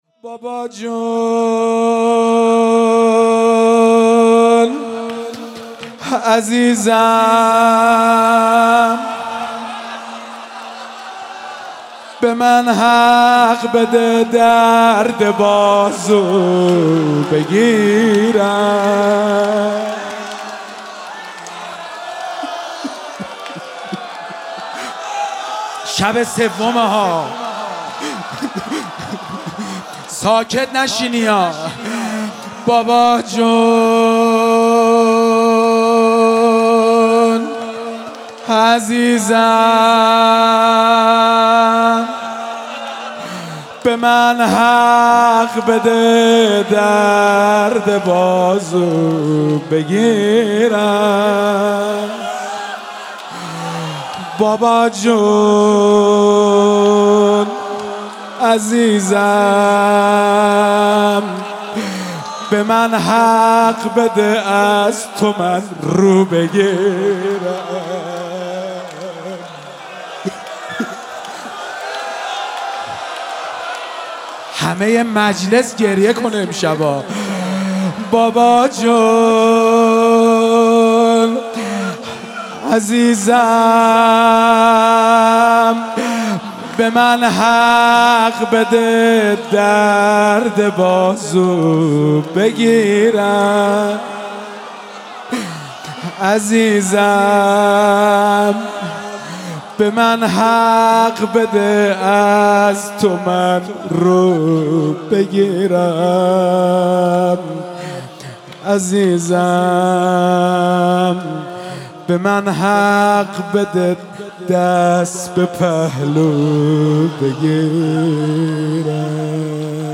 مداحی شب سوم محرم
در هیئت عبدالله بن الحسن